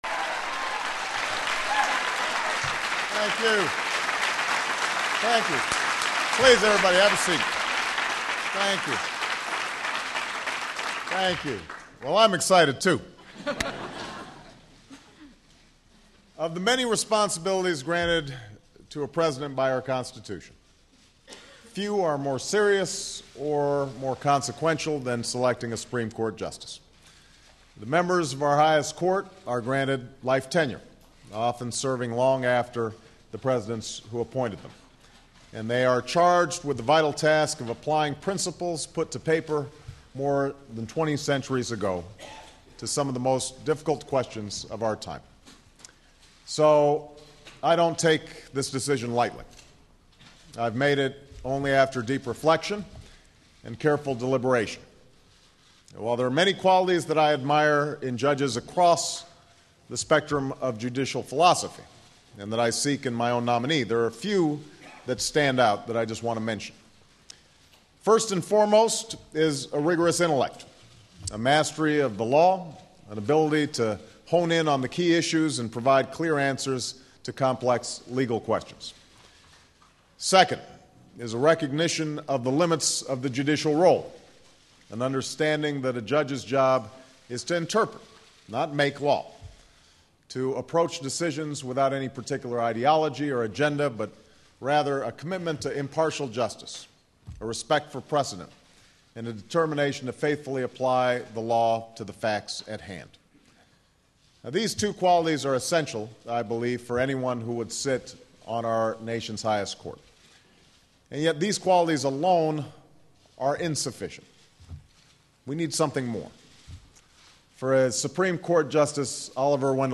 The text of President Obama's news conference Tuesday to announce his selection of Judge Sonia Sotomayor, as released by the White House.